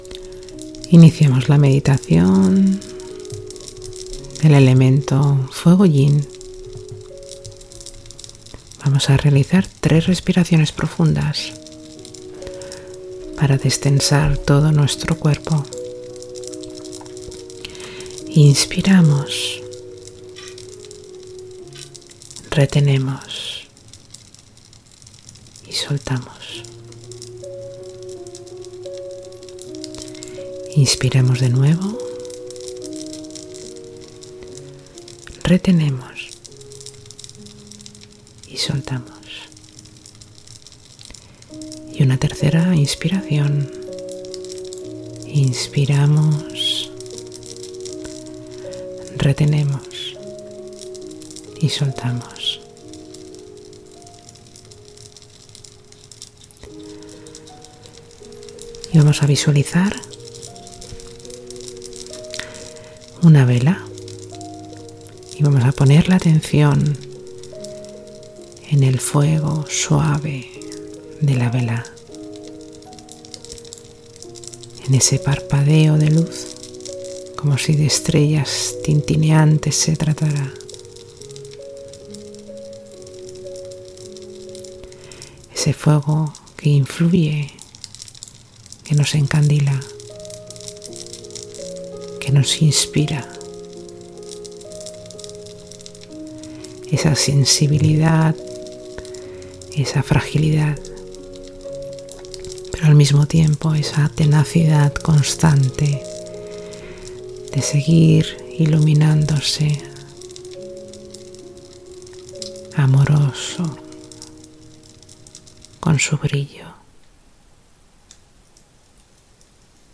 Meditación – Fuego Yin